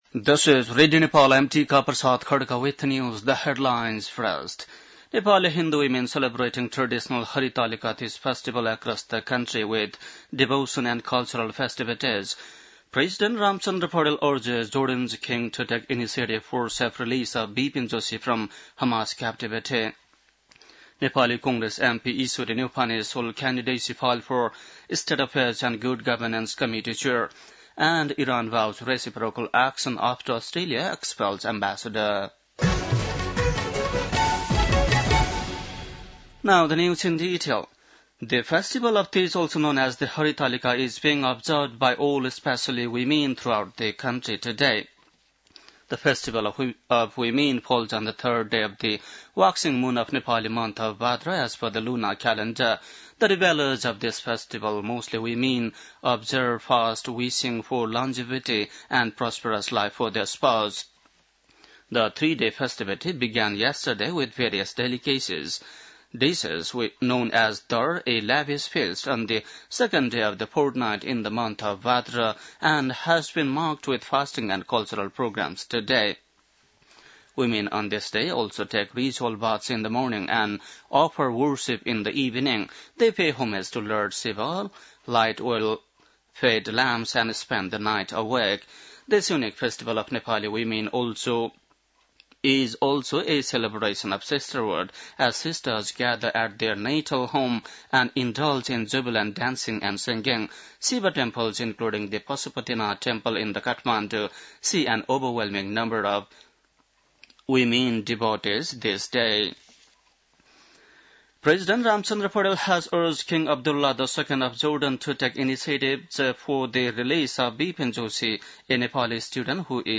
बेलुकी ८ बजेको अङ्ग्रेजी समाचार : १० भदौ , २०८२